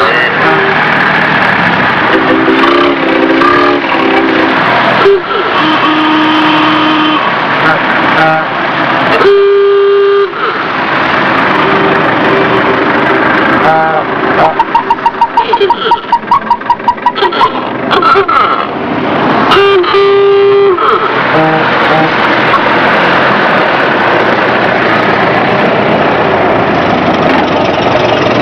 traffic.wav